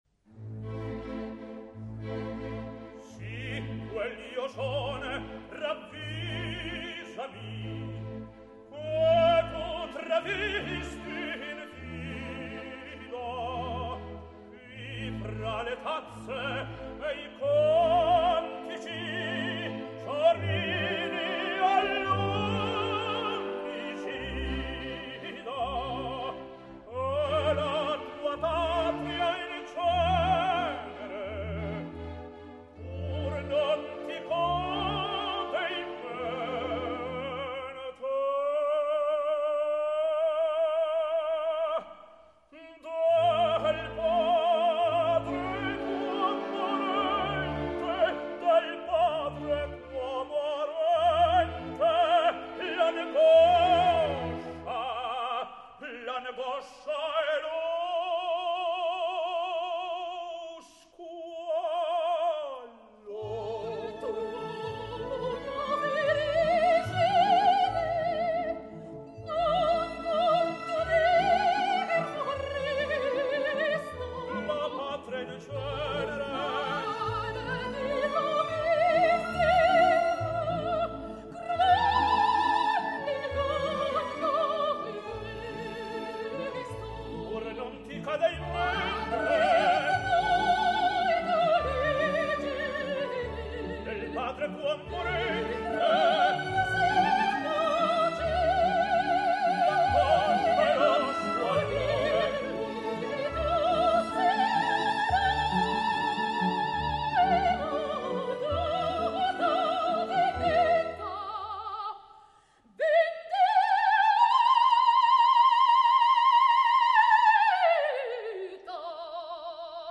Odabella [Sopran]
Foresto [Tenor]